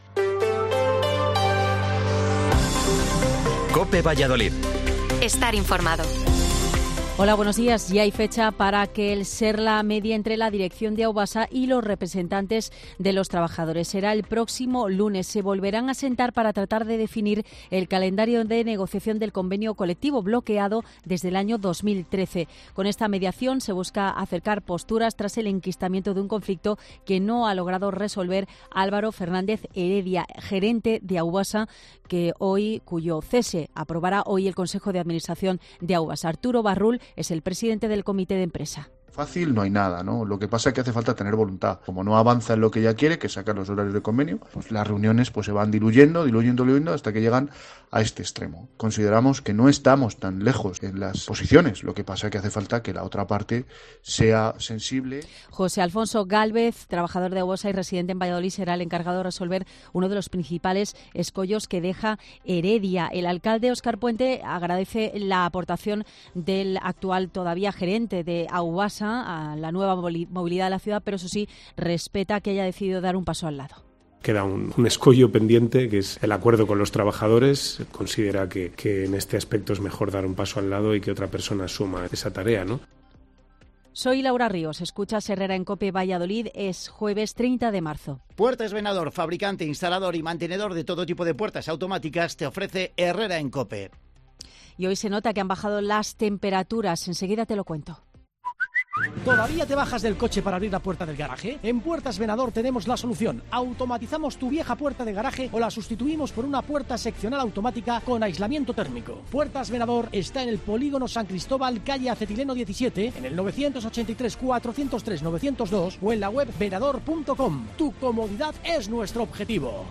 Informativo Matinal 8:24